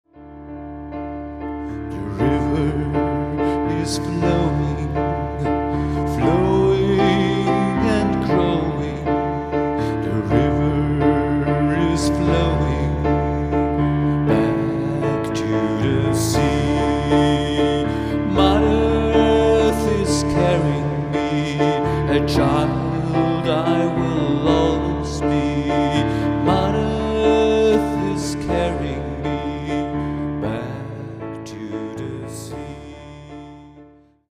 indianisch-schamanisch